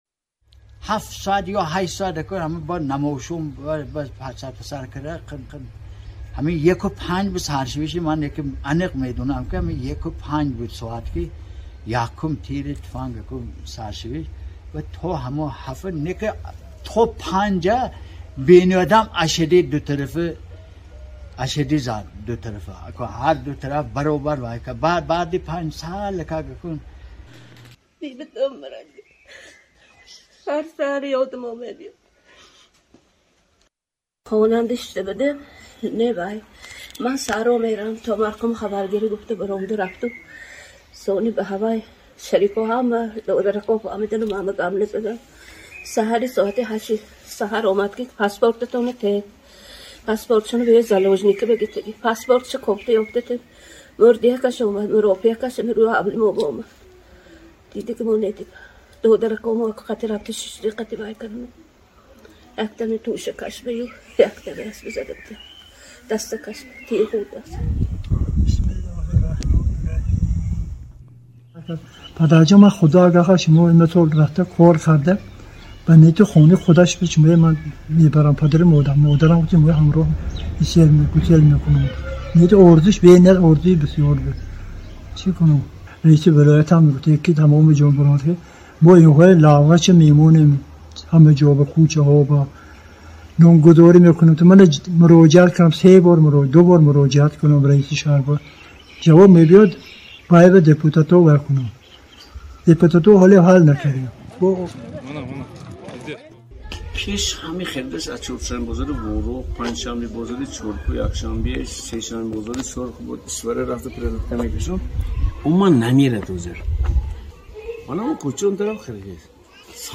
теъдоде аз сокинони Чоркӯҳ